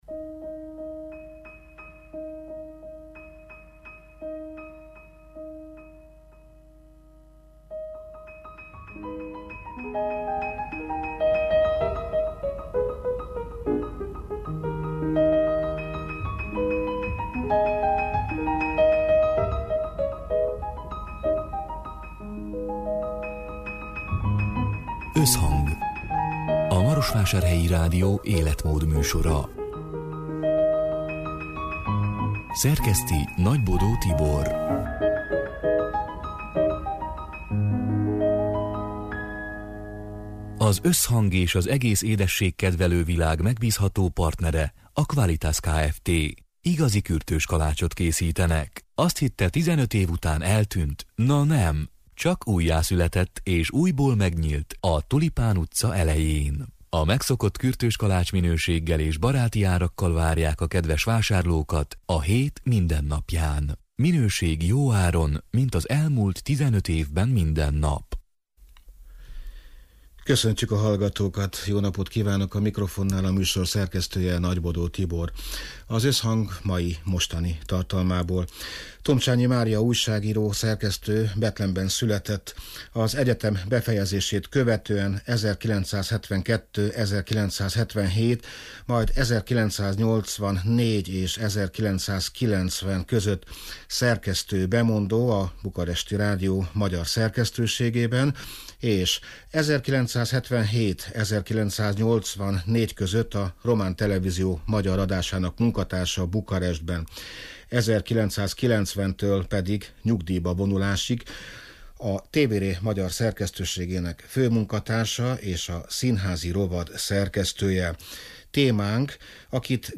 (2022. február 9-én, szerdán délután hat órától élőben)